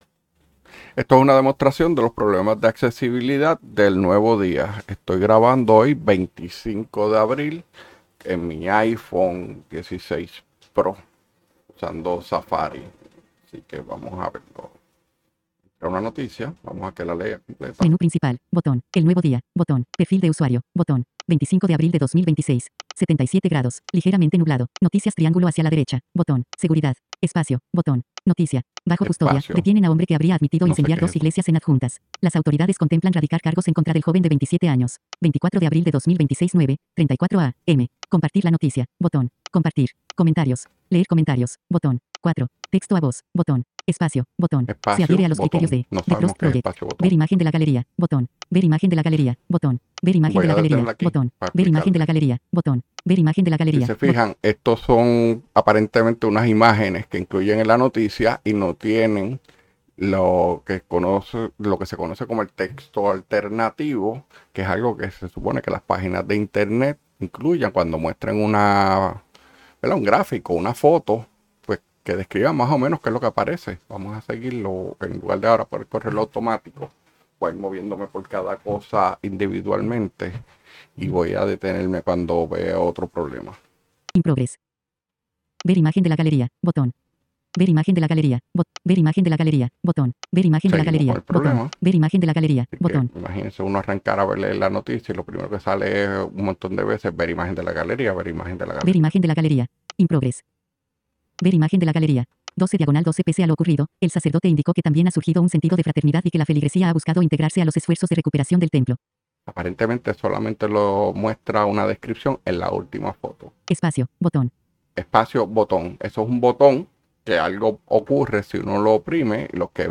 Incluyo una grabación que hice en la que demuestro los problemas de accesibilidad de END cuando trato de acceder a su contenido usando el app de ellos en mi iPhone.